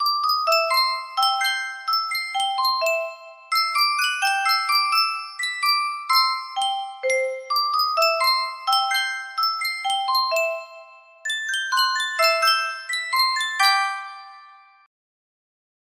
Superstar Music Box - Scott Joplin The Entertainer 6Z music box melody
Full range 60